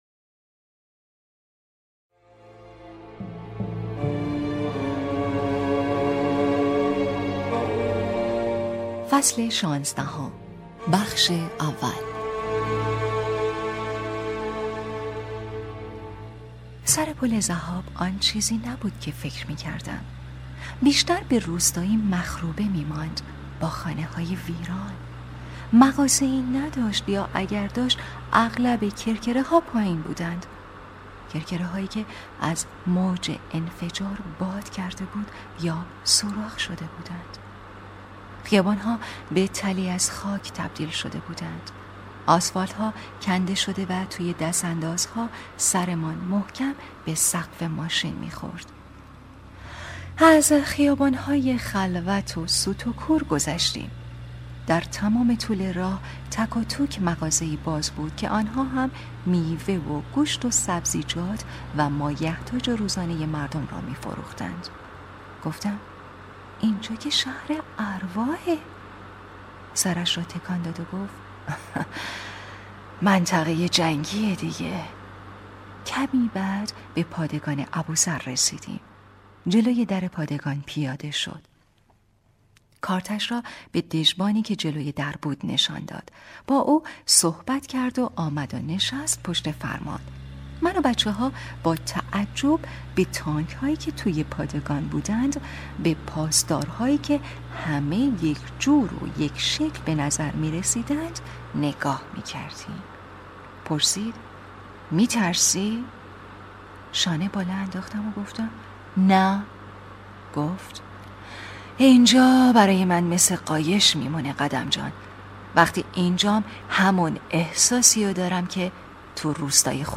کتاب صوتی | دختر شینا (15)